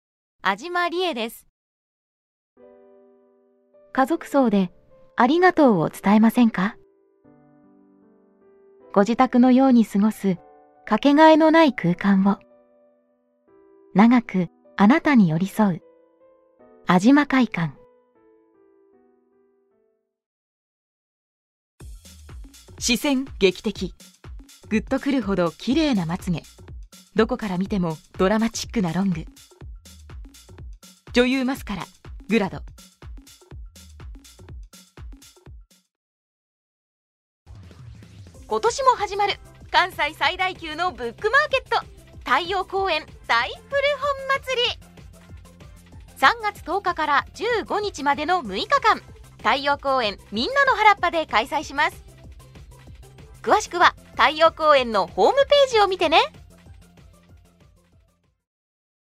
ボイスサンプル
• 明るいハキハキボイス
• 音域：高～中音
• 声の特徴：明るい、元気、爽やか
• CM